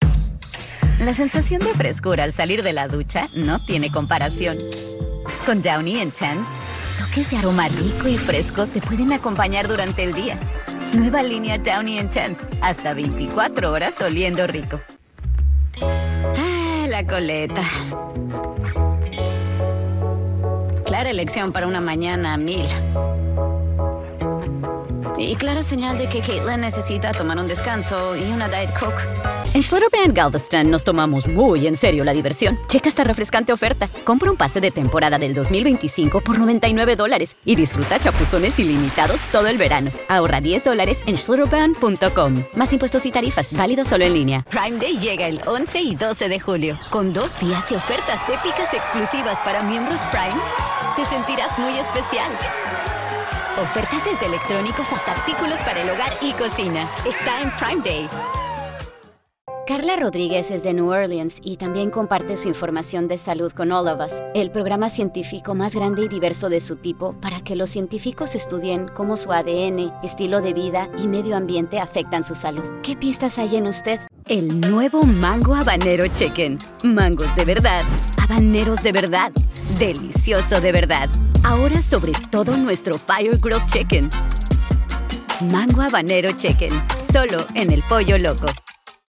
Demonstração Comercial
Idade da voz
Jovem adulto
Meia-idade